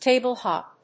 アクセントtáble‐hòp